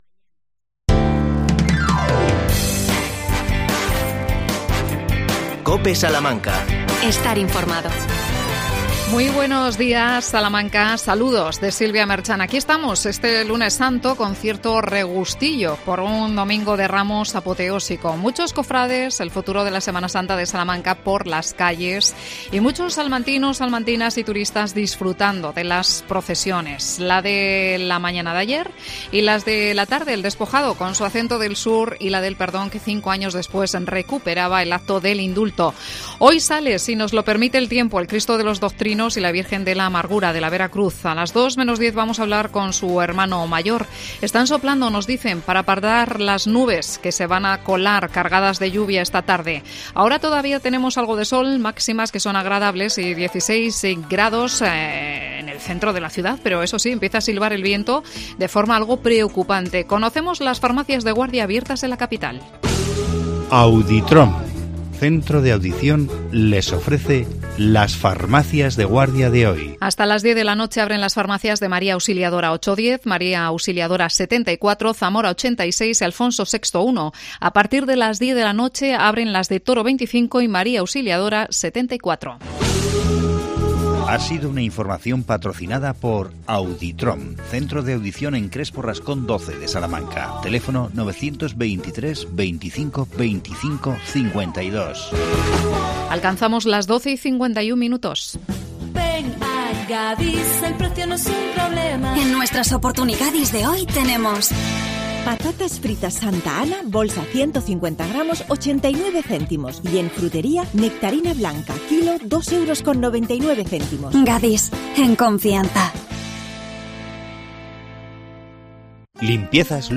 AUDIO: Regresa La Pasión a Ciudad Rodrigo. Entrevistamos